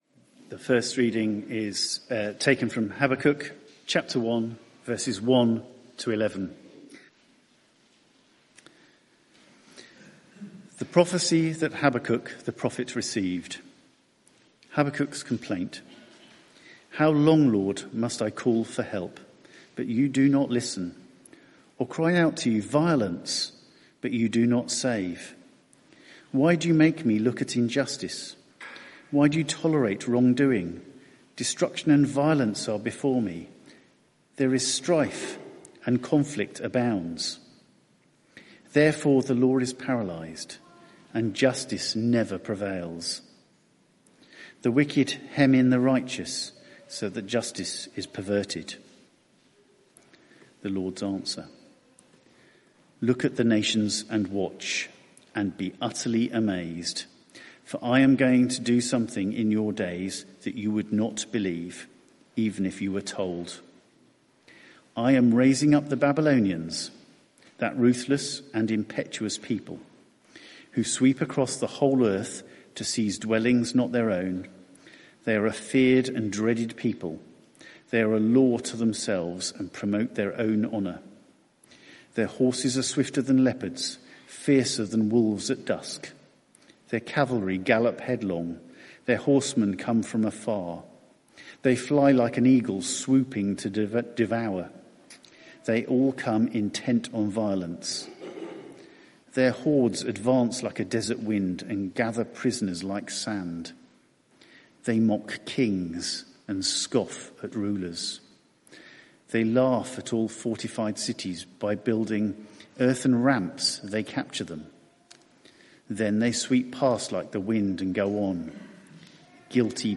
Media for 6:30pm Service on Sun 08th Jun 2025 18:30 Speaker
Sermon (audio) Search the media library There are recordings here going back several years.